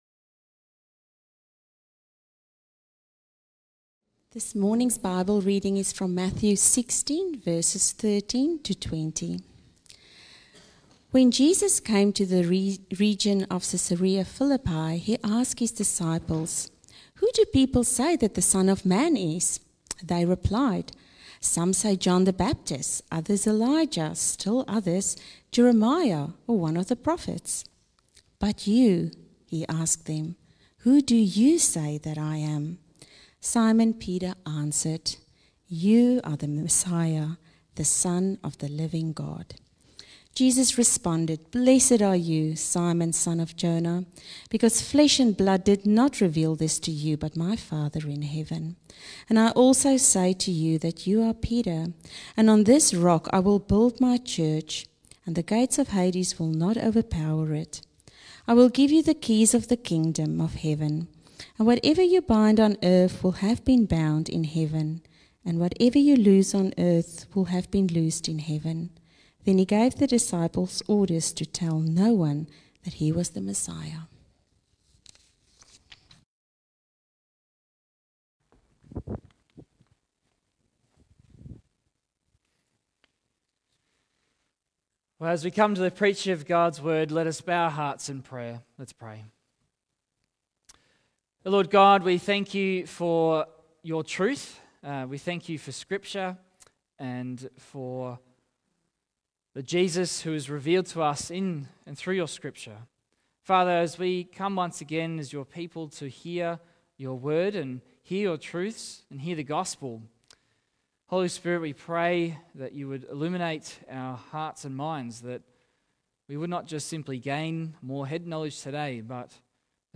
Sermons | Wonga Park Christian Reformed Church